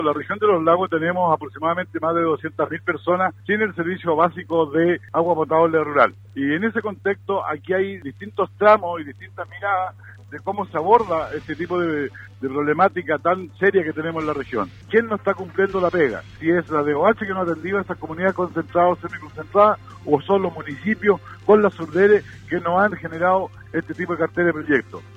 En conversación con Radio Sago, el presidente del Consejo Regional de Los Lagos, Juan Cárcamo, se refirió a los pocos proyectos de Agua Potable Rural que han sido presentados al Core.